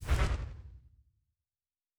pgs/Assets/Audio/Fantasy Interface Sounds/Special Click 32.wav at master
Special Click 32.wav